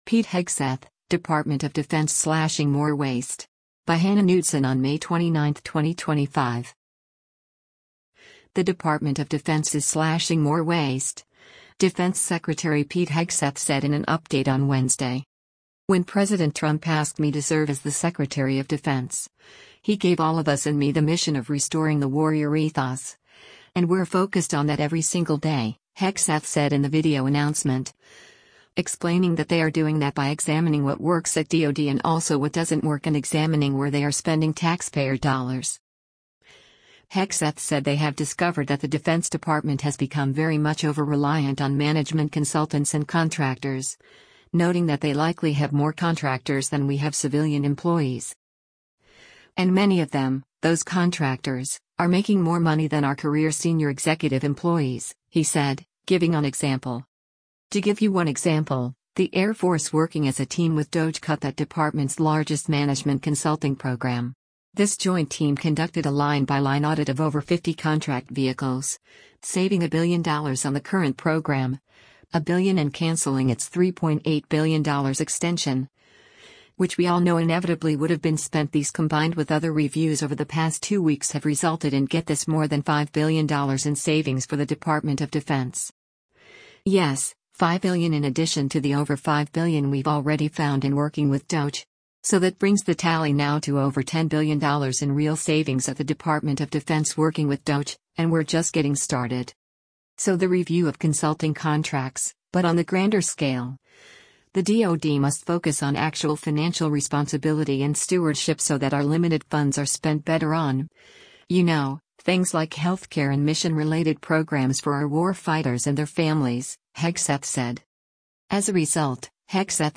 The Department of Defense is slashing more waste, Defense Secretary Pete Hegseth said in an update on Wednesday.